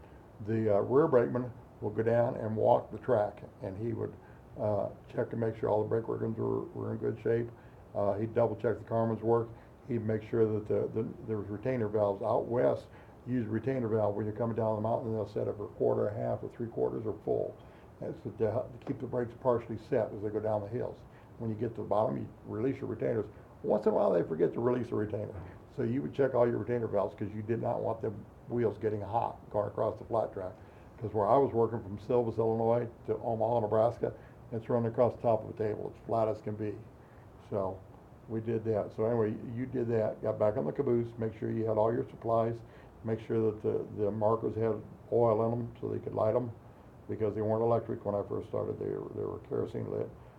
Interview Clip